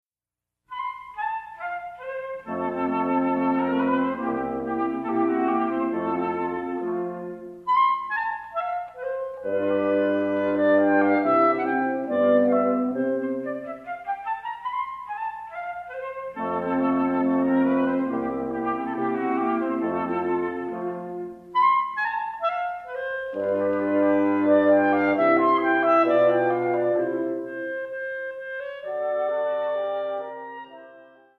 for Flute, Clarinet, Horn, and Bassoon